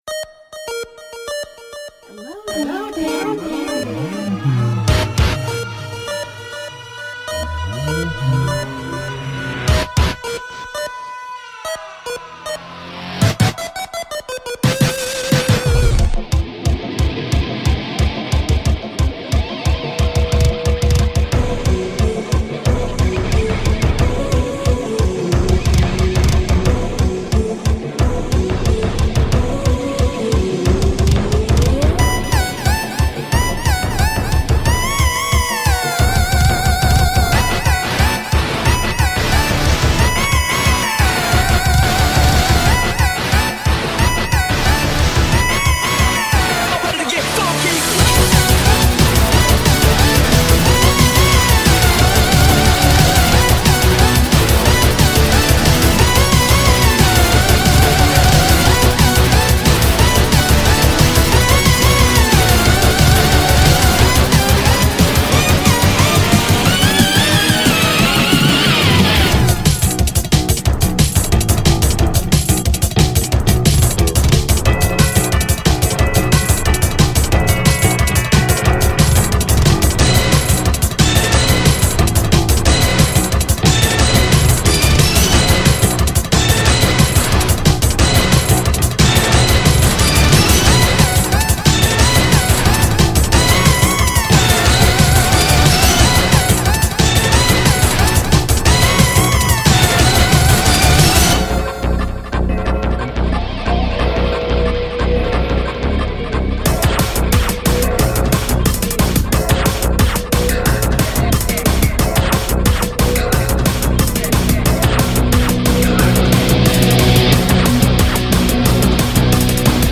BPM180-276
Audio QualityPerfect (High Quality)
VGM song for StepMania, ITGmania, Project Outfox
Full Length Song (not arcade length cut)